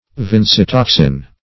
Search Result for " vincetoxin" : The Collaborative International Dictionary of English v.0.48: Vincetoxin \Vin`ce*tox"in\, n. (Chem.)